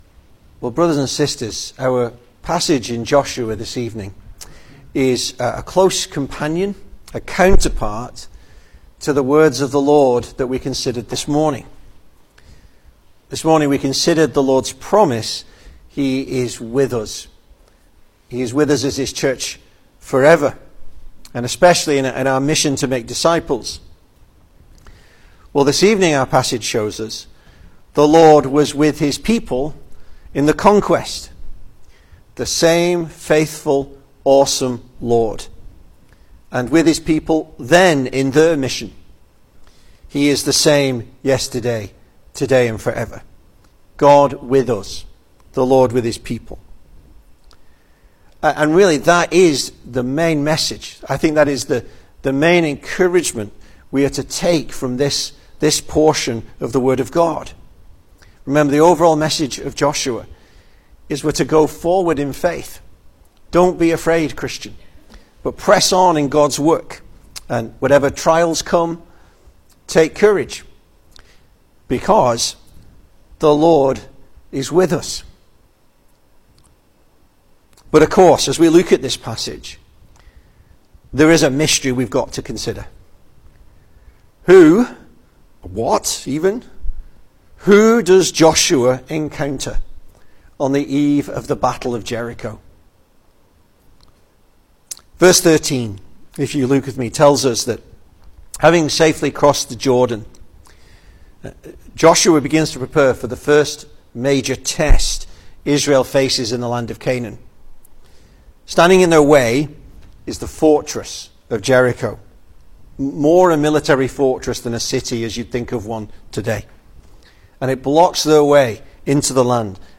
2025 Service Type: Sunday Evening Speaker